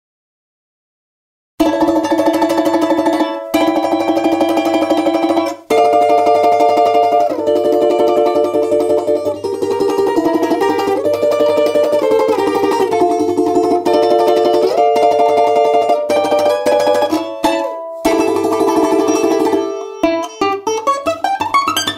Балалаечная мелодия